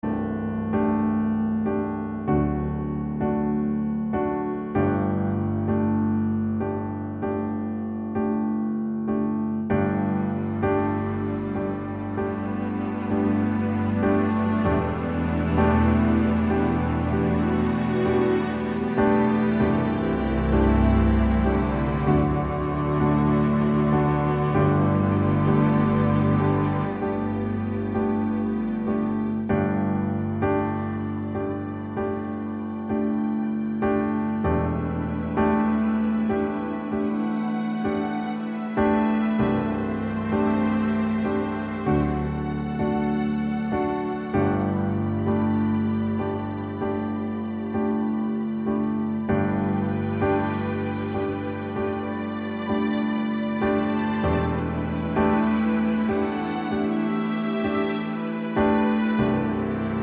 Lower Male Key